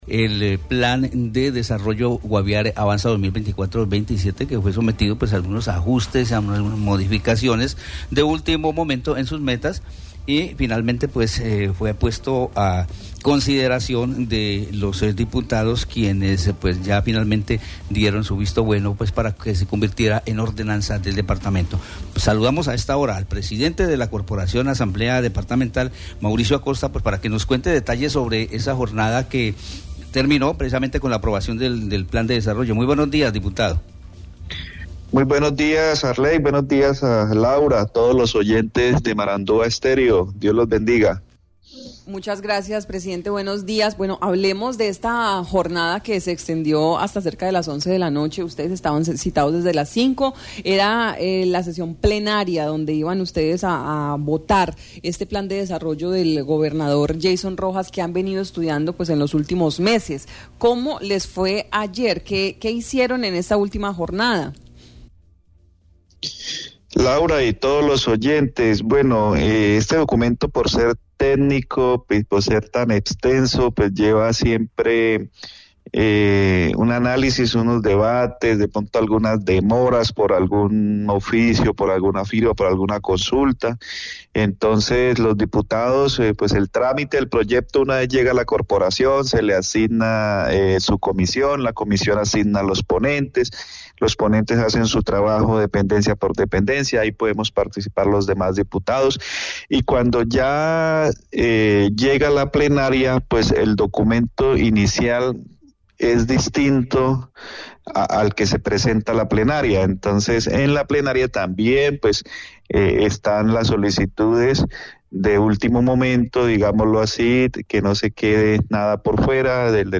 El diputado Mauricio Acosta, presidente de la Asamblea del Guaviare, habló sobre el proceso adelantado con este documento durante tres meses para su análisis, estudio y modificaciones para después de todo esto ser aprobado por los diputados para que se convirtiera en la Ordenanza Número 520 Plan de Desarrollo “Guaviare Avanza 2024-2027”.